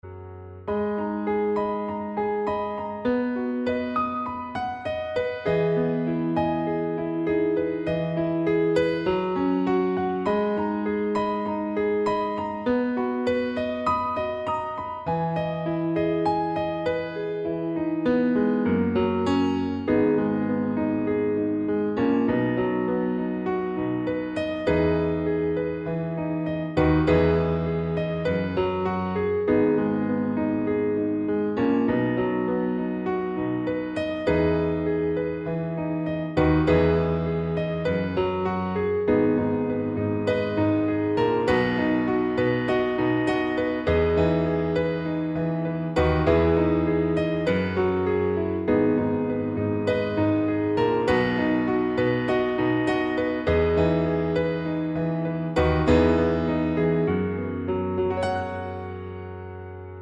Piano Only Original Female Key